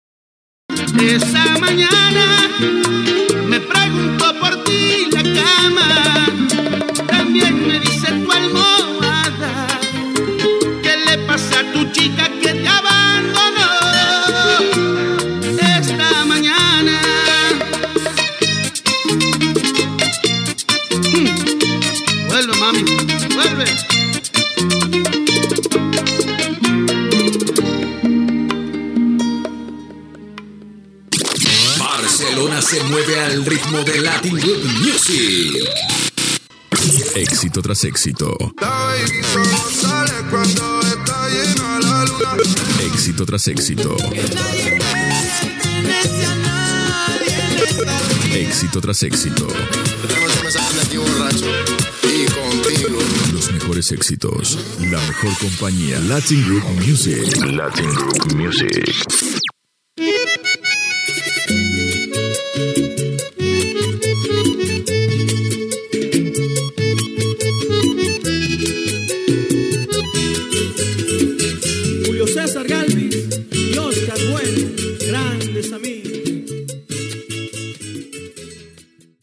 Tema musical i indicatiu de l'emissora